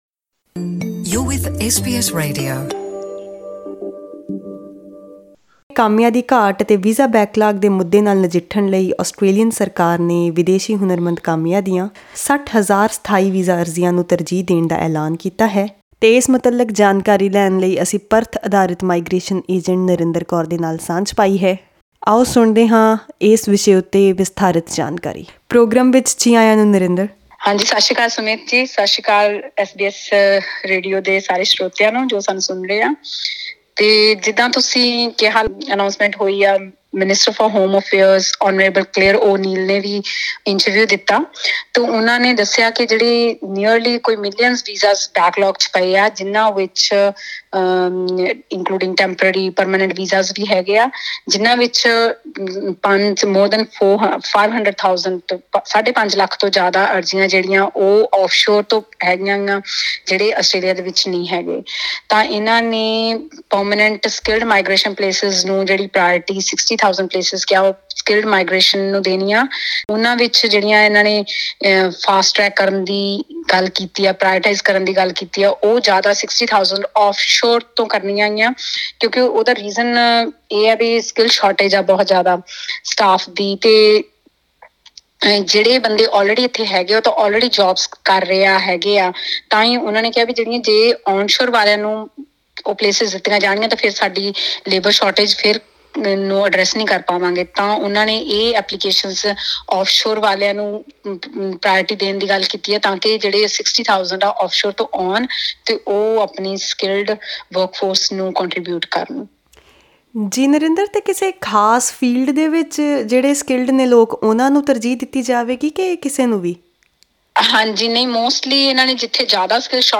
Click on the audio player to listen to this conversation in Punjabi.